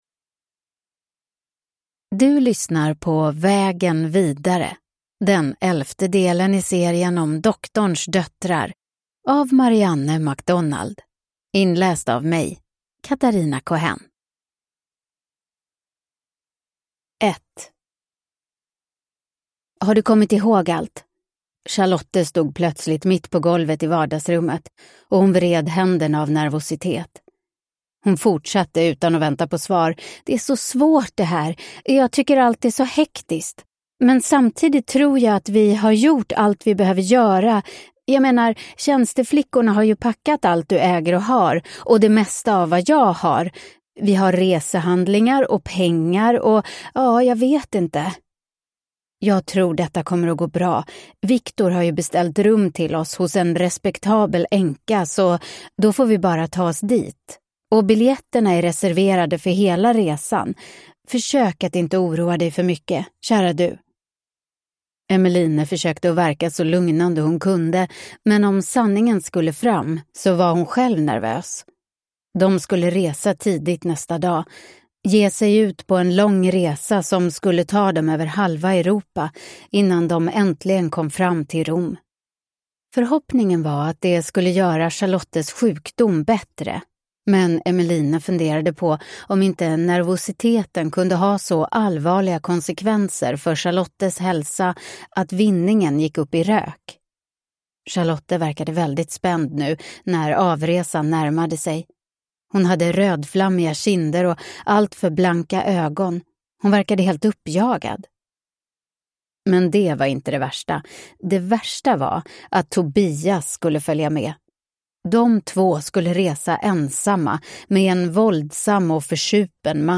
Vägen vidare – Ljudbok